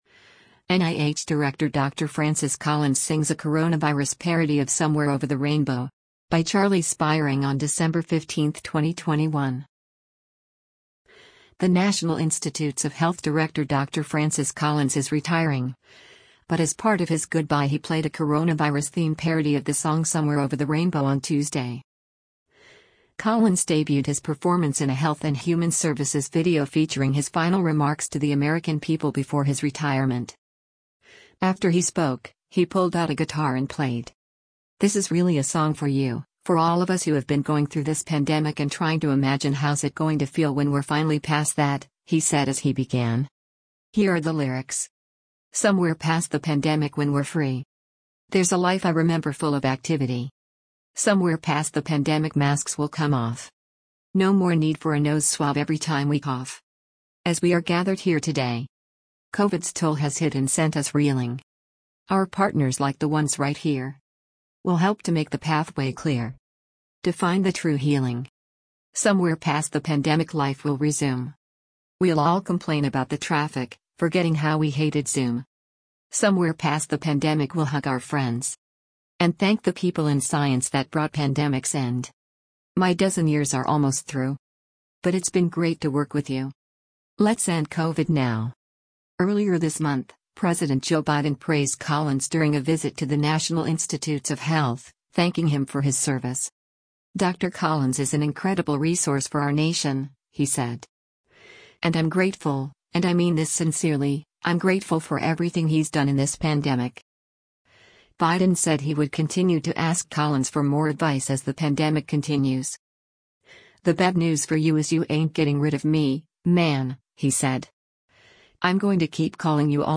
After he spoke, he pulled out a guitar and played.